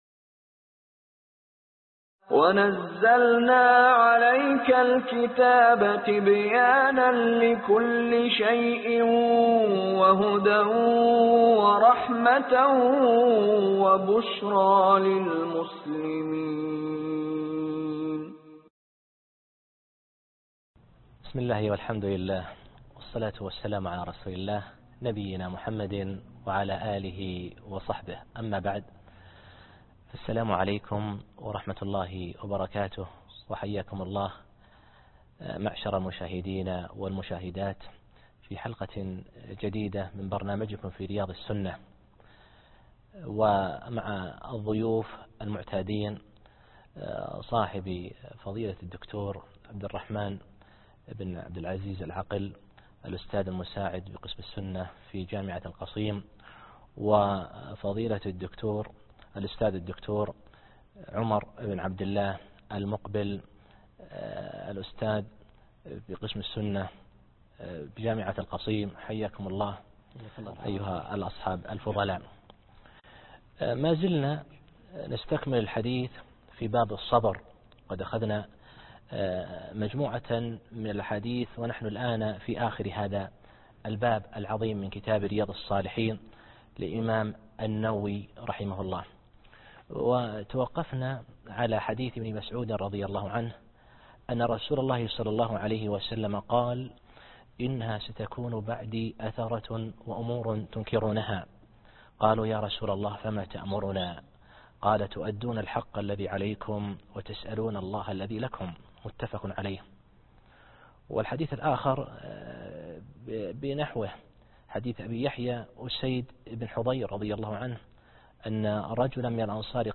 الدرس( 14)باب الصبر ج5-في رياض السنة الموسم الاول